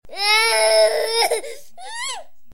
cry4.wav